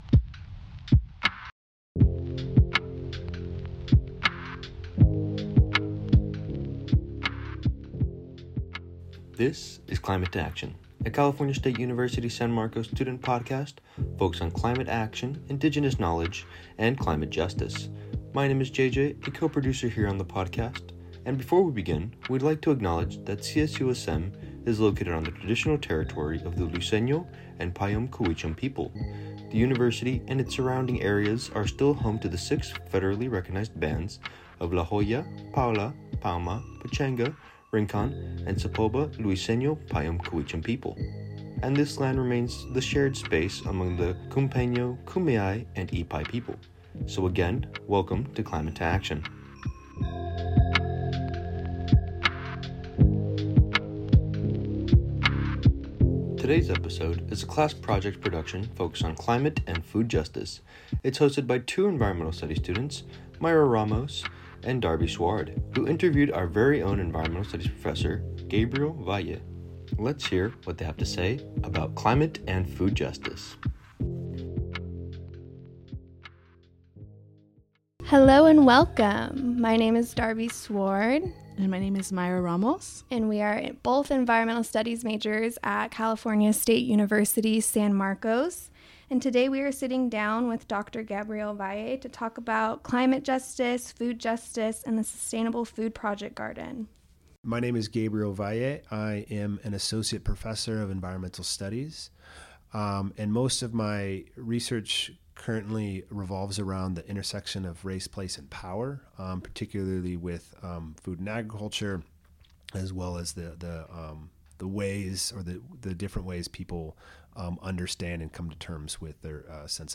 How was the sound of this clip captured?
Climate To Action is produced at the CSUSM Inspiration Studios.